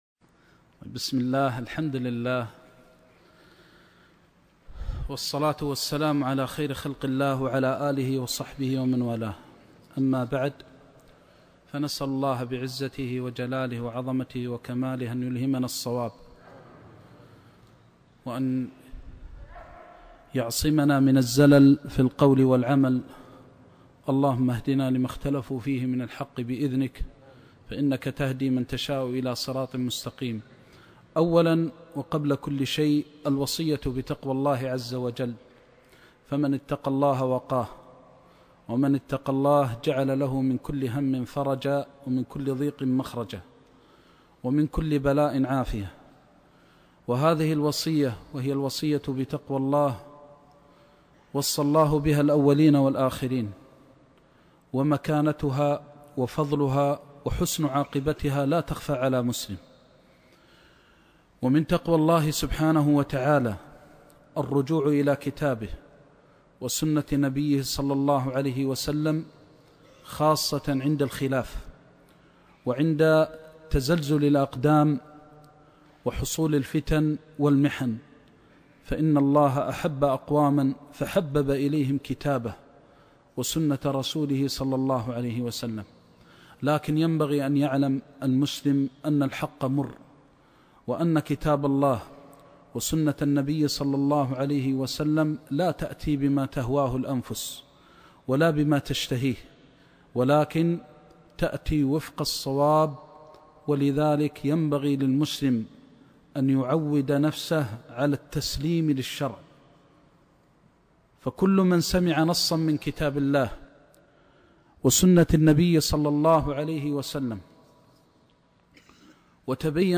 حديث درس عمدة الأحكام(جدة)